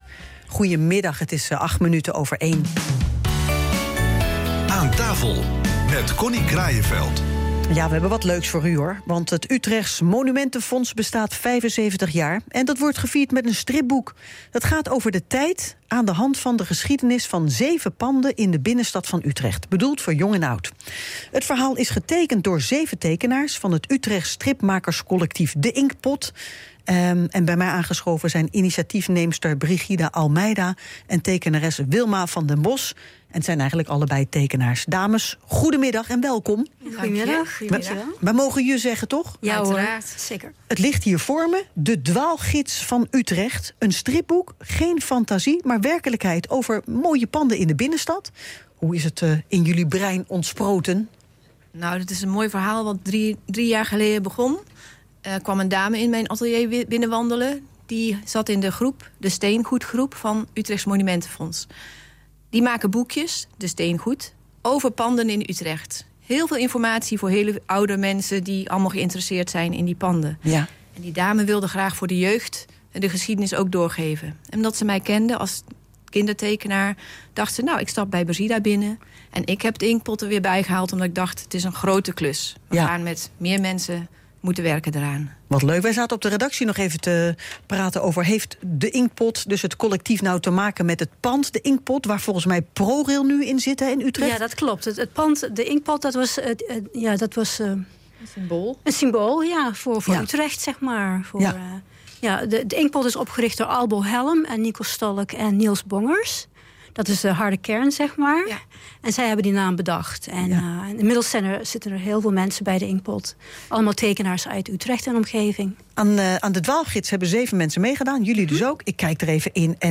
interview_dwaalgids.mp3